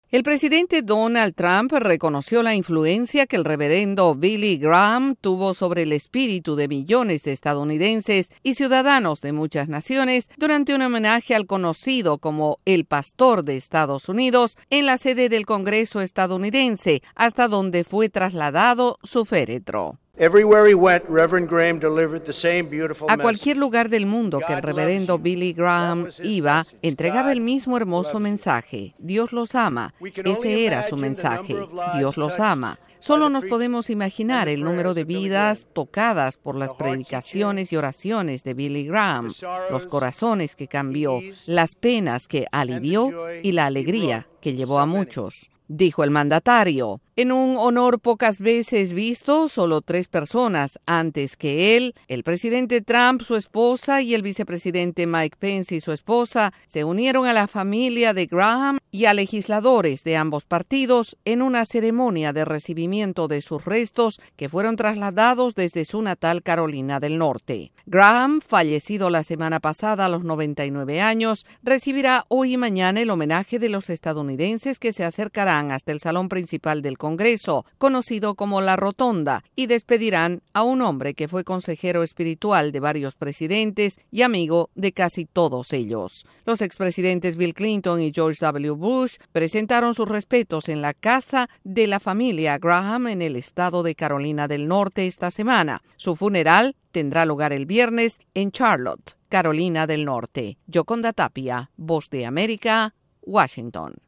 El féretro del reverendo Billy Graham llegó hoy al Capitolio, la sede del Congreso estadounidense, para recibir honores por una vida dedicada a brindar mensajes de paz y amor al prójimo. Desde la Voz de América en Washington DC informa